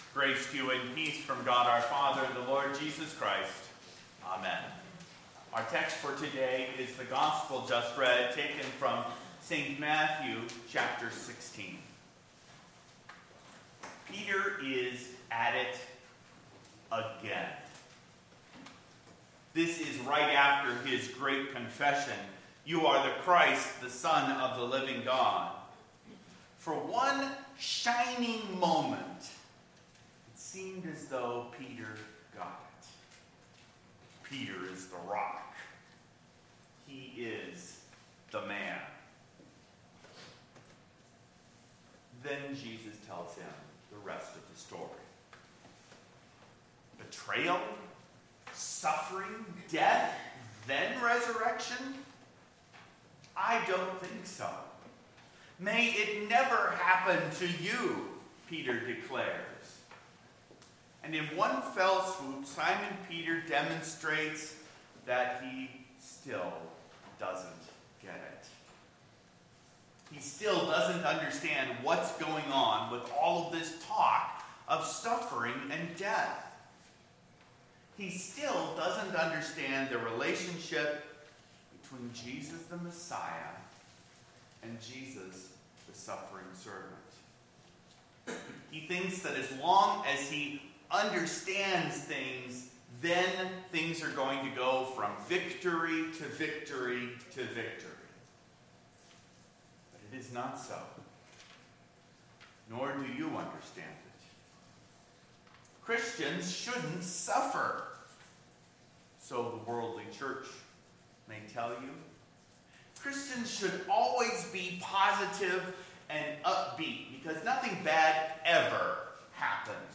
Sermon-8-31-14.mp3